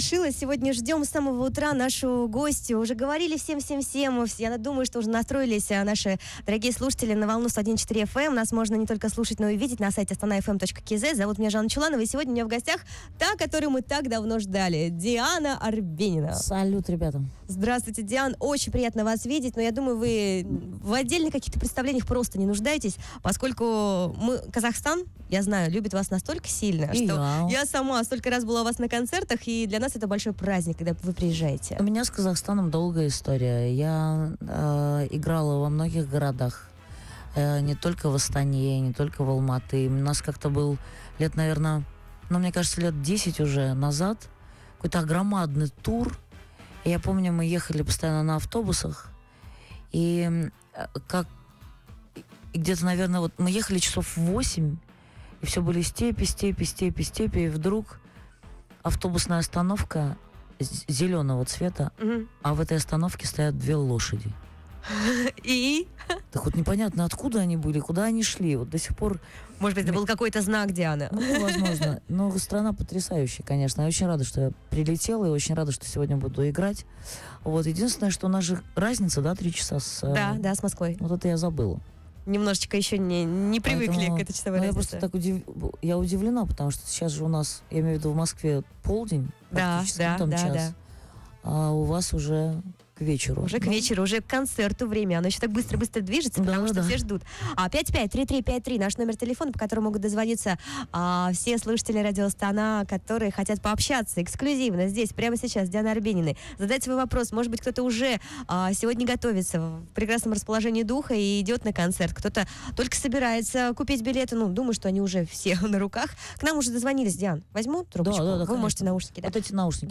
Здесь группа сыграет три концерта: в Астане, Караганде и Алматы. За несколько часов до начала концерта в Конгресс-Холле Астаны Диана Арбенина дала интервью на радио Astana FM.